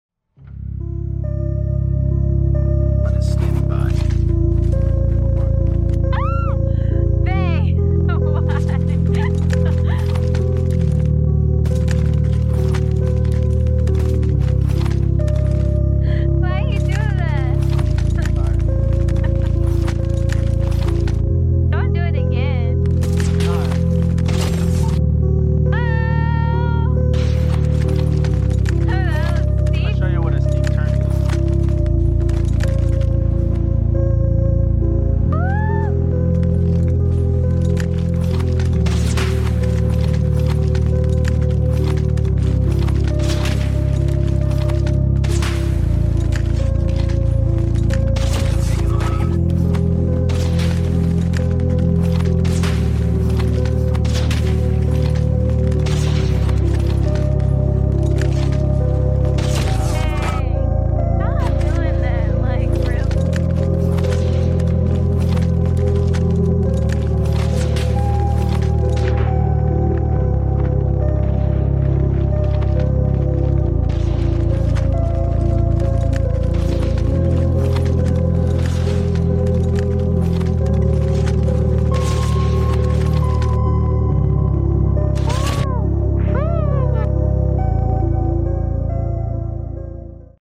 Relax To Some Movement ASMR sound effects free download